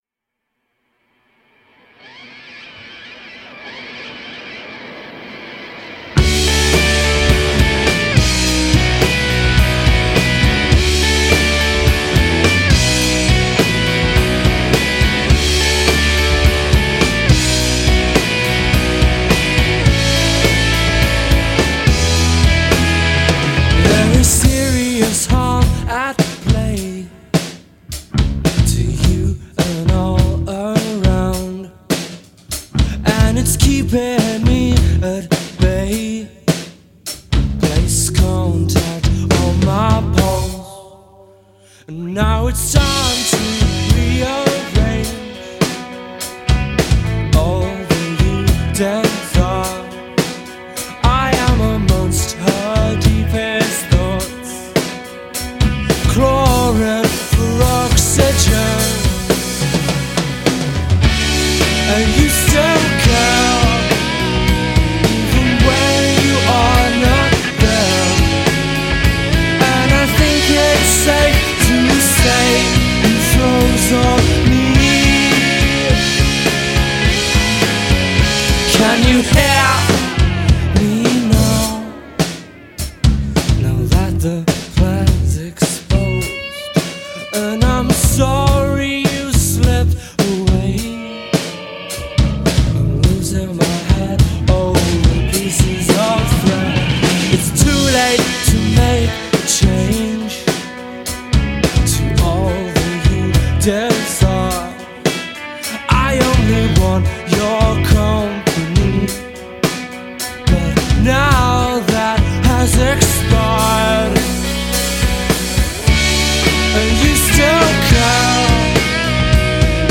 Post rockers
vocals/rhythm guitar
bass
lead guitar/backing vocals
drums